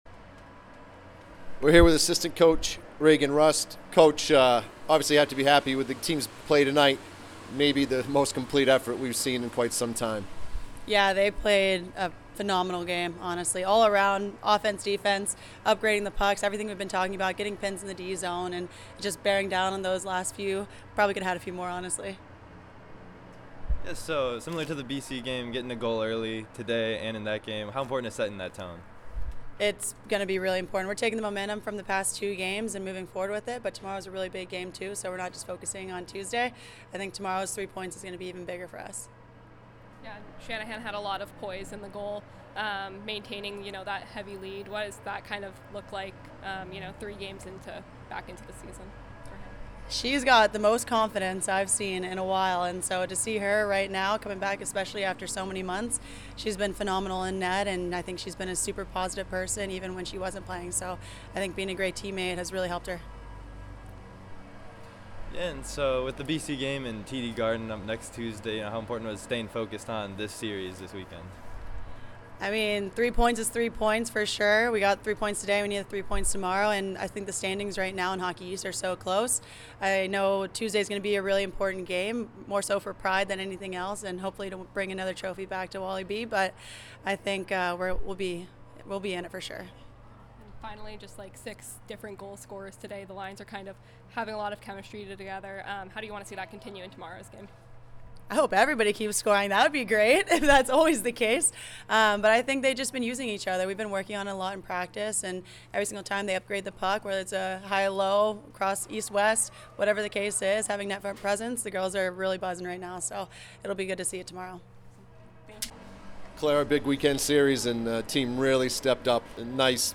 Vermont Postgame Interview